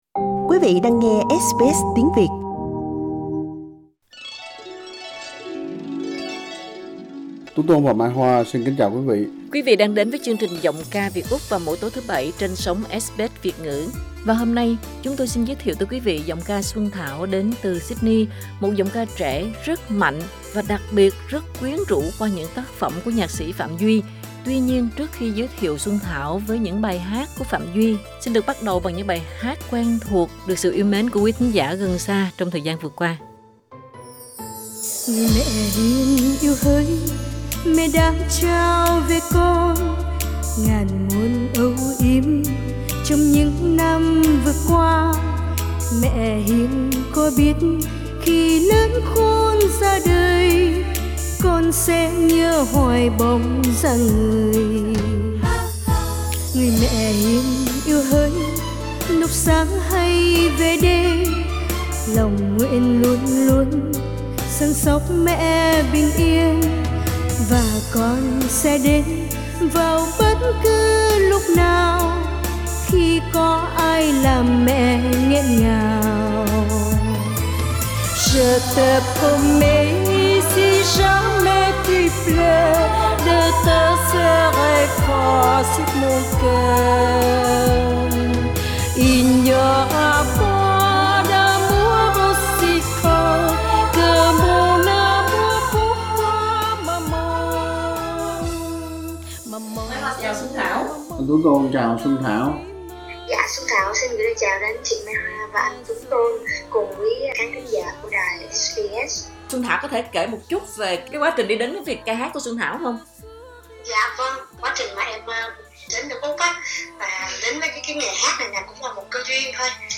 Sở hữu một giọng ca truyền cảm và đầy nội lực